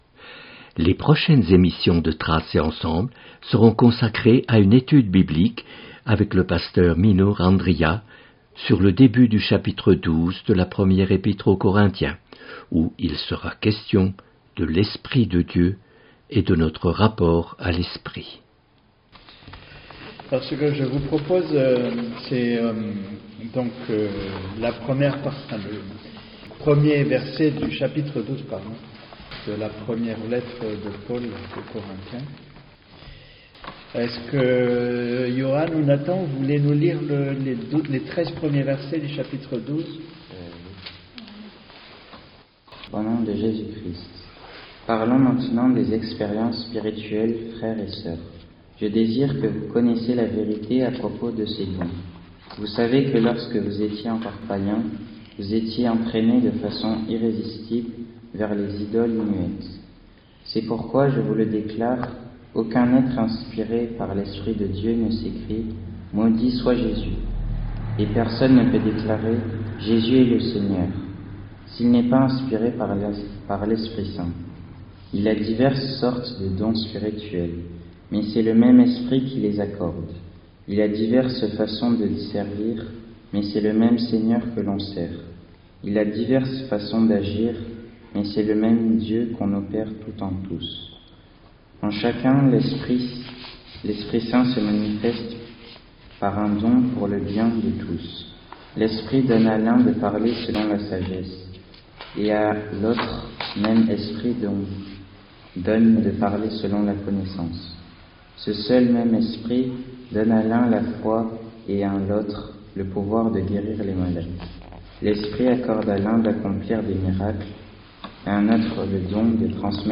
Etude biblique